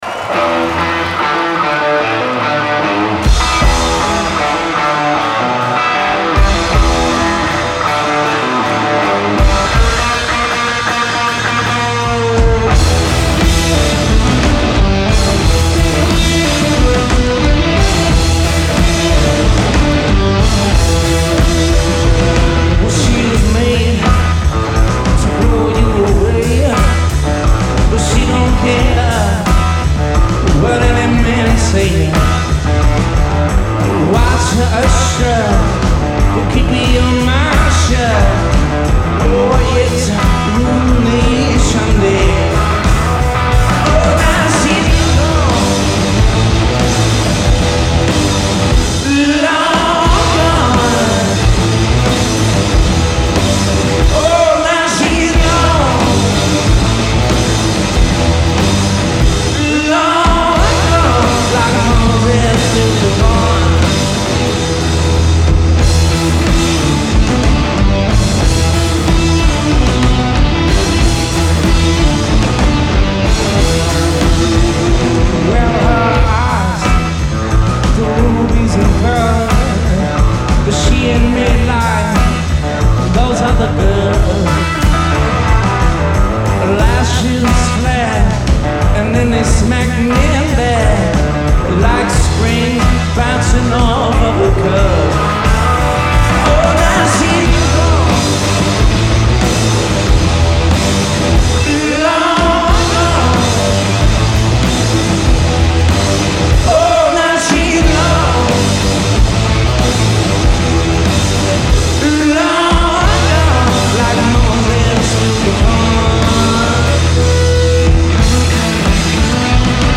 Genre : Alternative, Indie
Live in Portland, ME